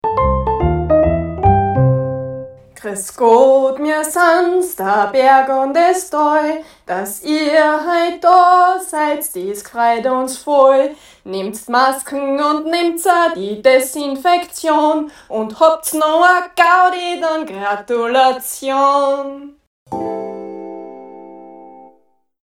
Auf der steirischen Planneralm hört man seit dem Vorjahr beim Anstellen zum Schilift, im Kinderland und bei der Liftkassa durch den Lautsprecher folgende nette Gstanzln zur Maskenpflicht:
Liftansagen_Planneralm_1.mp3